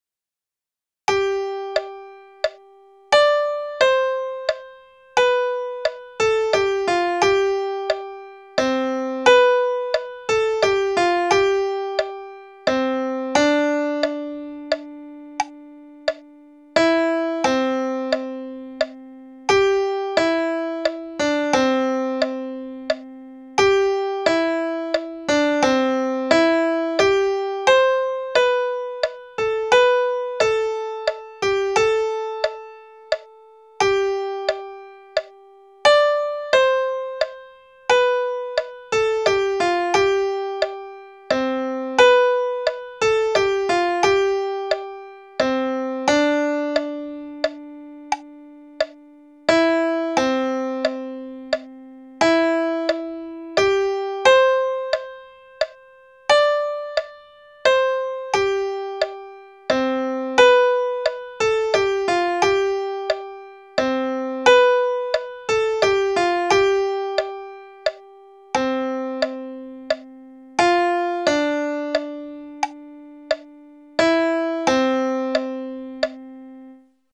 flauta dulce